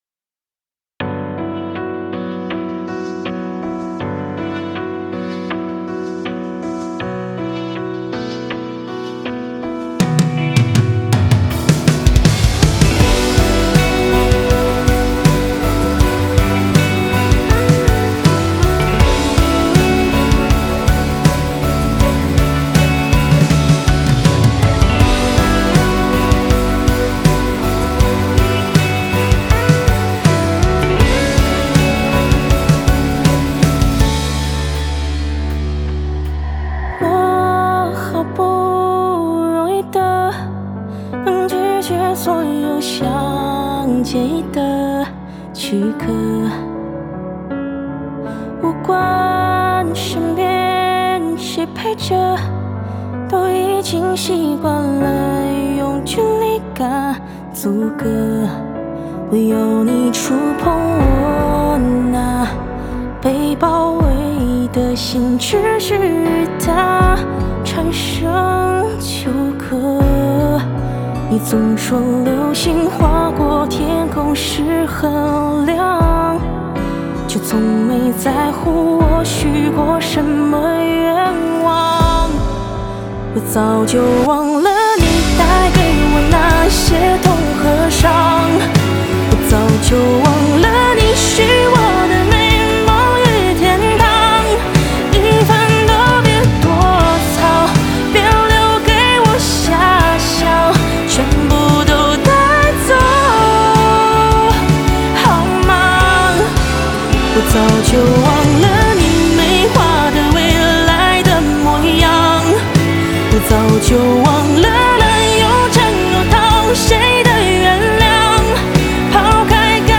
吉他
和声